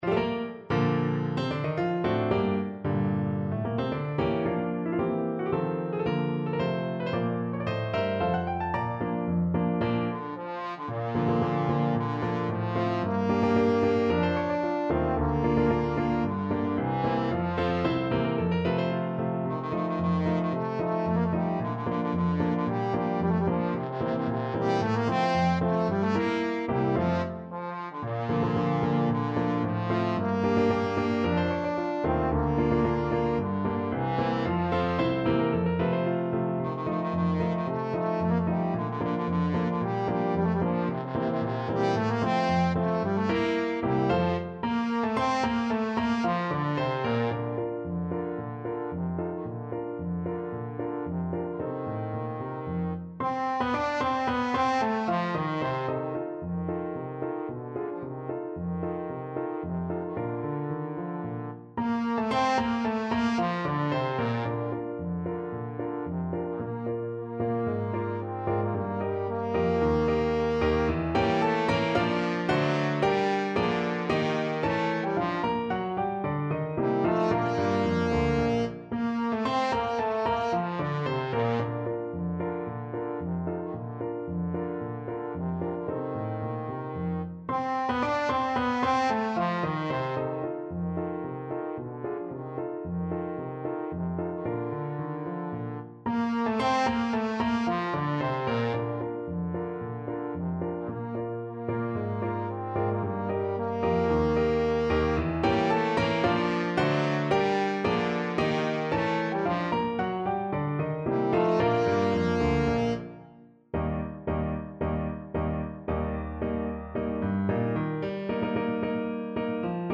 Trombone
Bb major (Sounding Pitch) (View more Bb major Music for Trombone )
March =c.112
2/2 (View more 2/2 Music)
G3-G5
Classical (View more Classical Trombone Music)